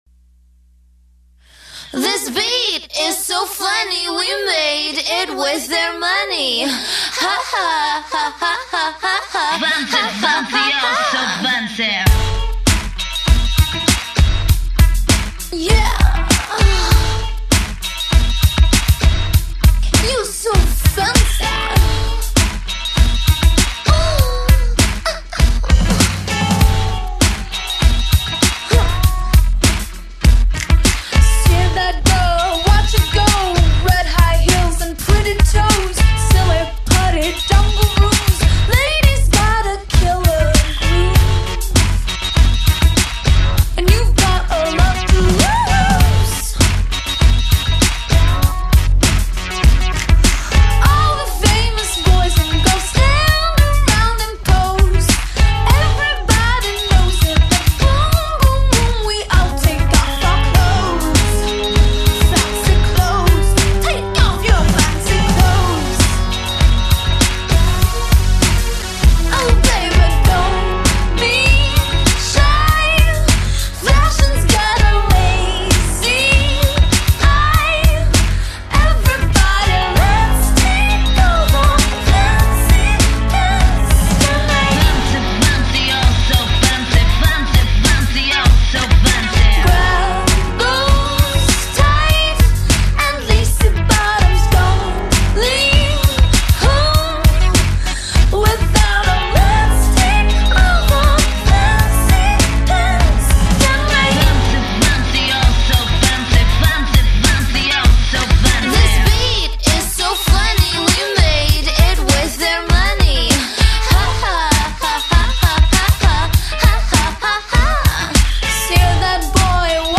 зарубежная эстрада